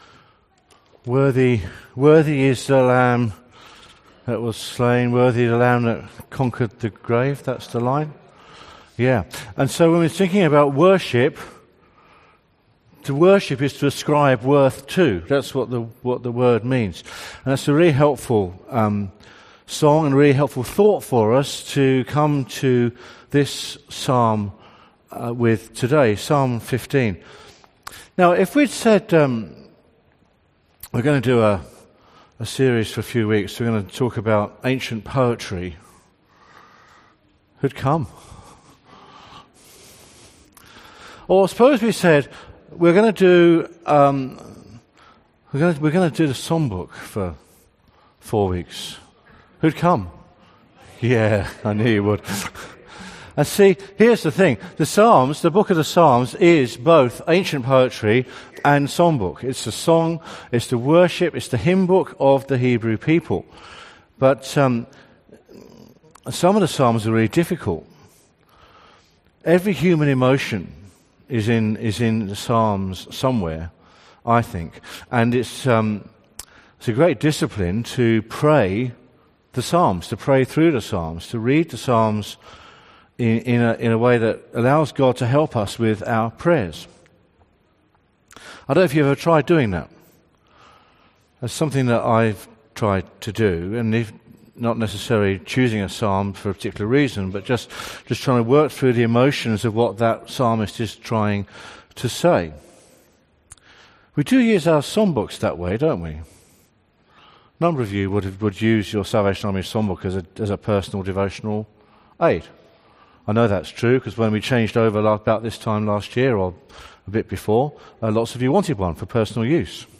Message from the 10AM meeting at Newcastle Worship & Community Centre of The Salvation Army. The Bible reading is Psalm 15.